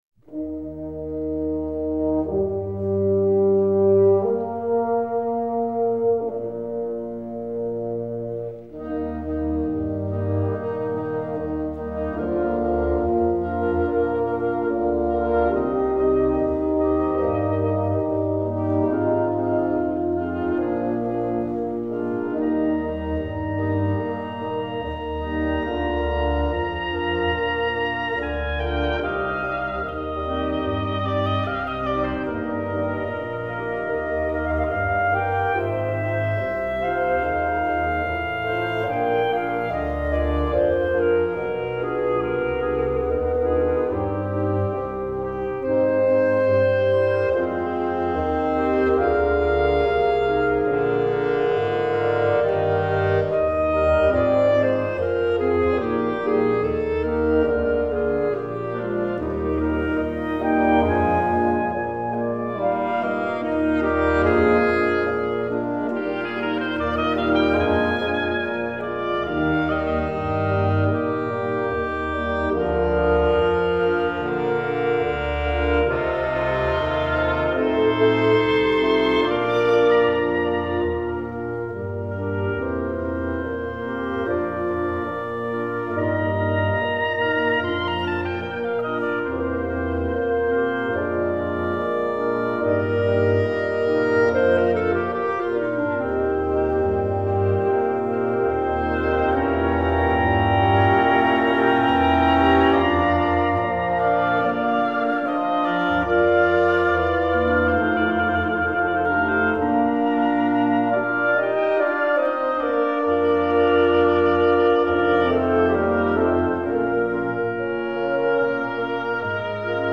Et à ceux qui ne jure que par lui, mais qui le connaissent mal, j'ai toujours un grand plaisir à attirer leur attention sur sa  sérénade dite Grande partita (KV 361), particulièrement sur son troisième mouvement :